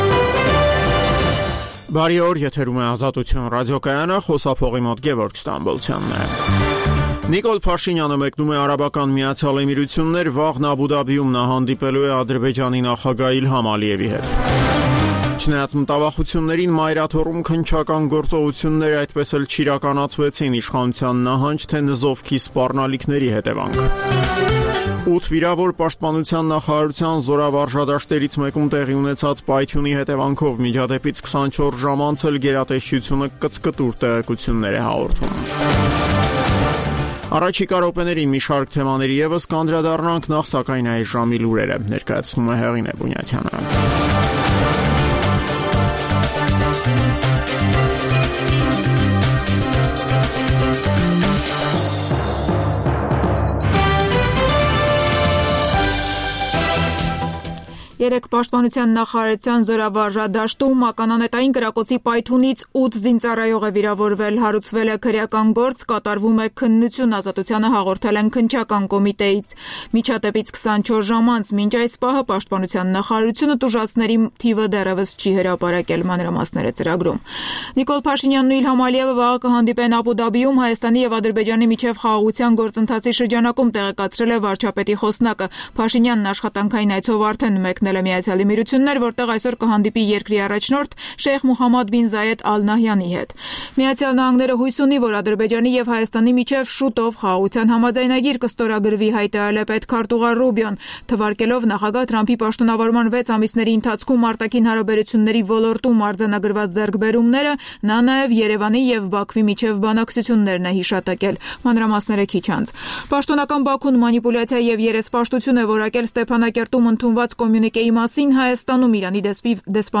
Տեղական եւ միջազգային լուրեր, ռեպորտաժներ, հարցազրույցներ: